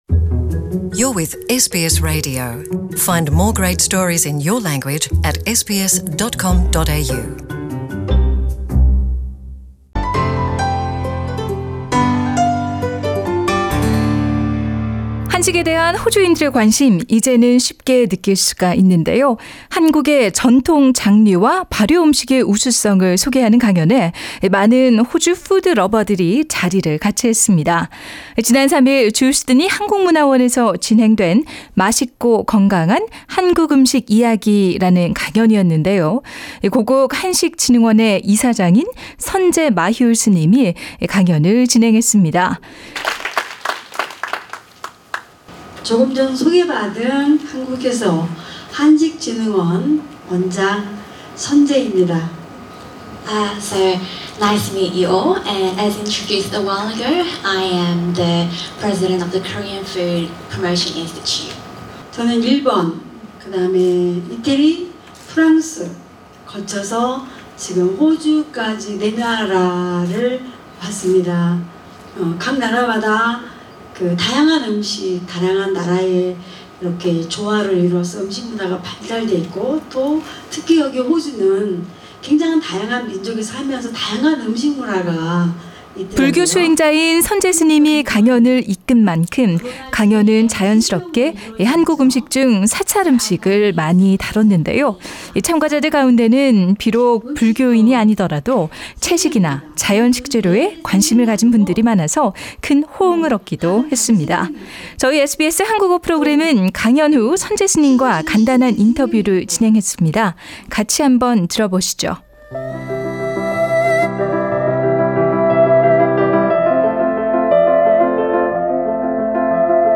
The lecture explores the distinctive nature of temple food and the use of fermentation in Korean food.